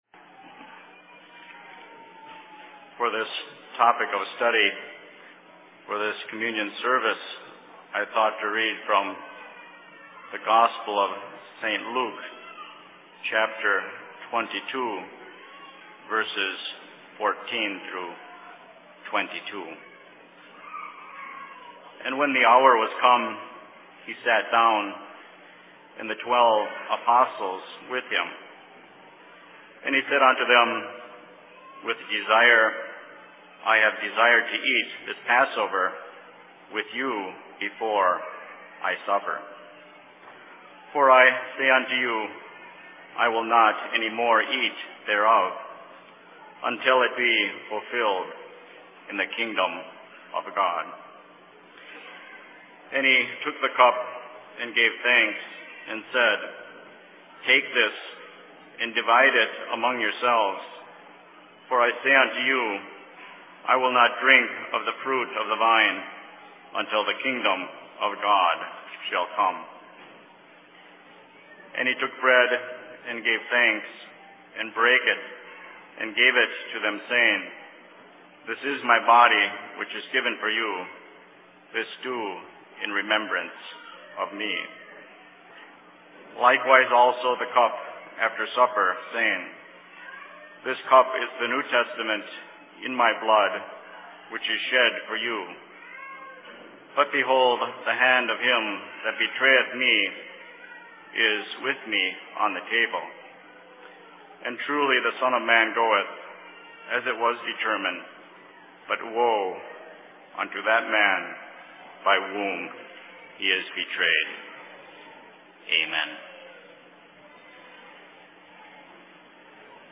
Communion Service/Sermon in LLC Summer Services 2004, Outlook, Saskatchewan, Canada 03.07.2004
Location: LLC 2004 Summer Services